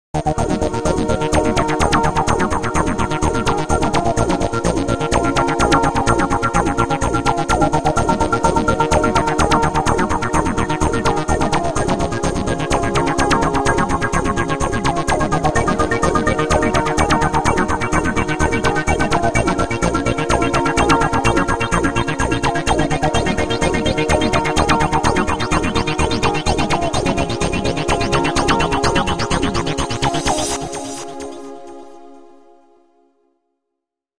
but they just sound like standard phone ringtones to me